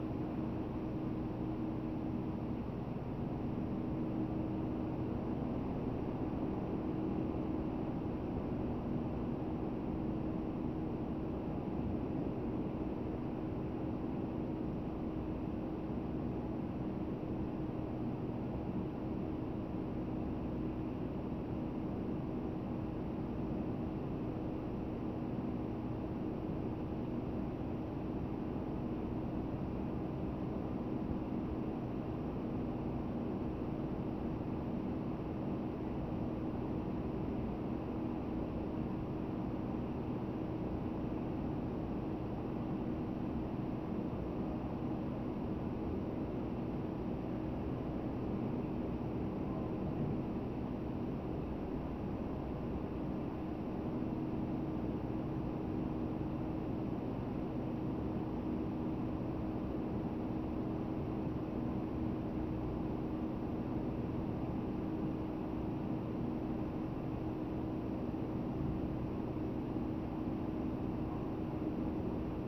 Evening Office Hallway ST450_ambiX.wav